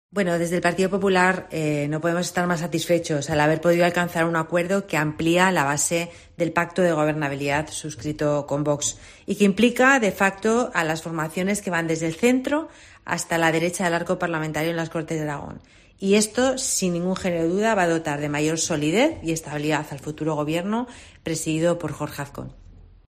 La portavoz del PP en las Cortes, Ana Alós, se muestra satisfecha del acuerdo alcanzado con el PAR.